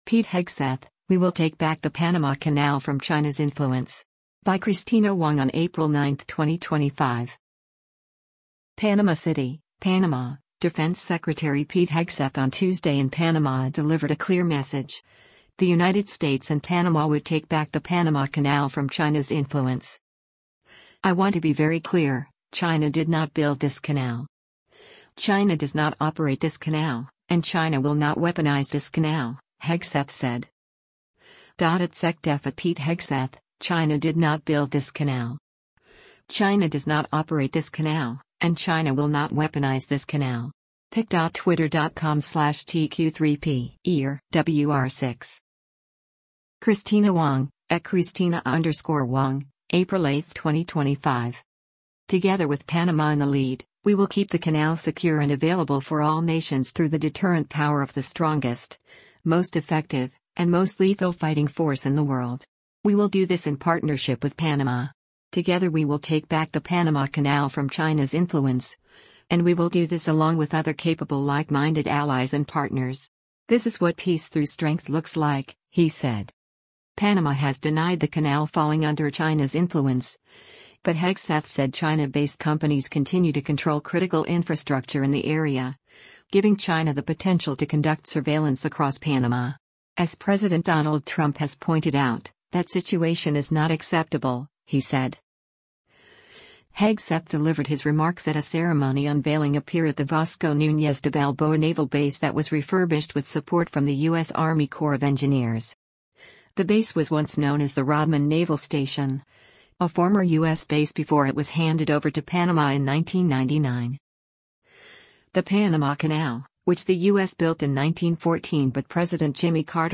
Hegseth delivered his remarks at a ceremony unveiling a pier at the Vasco Nuñez de Balboa Naval Base that was refurbished with support from the U.S. Army Corps of Engineers.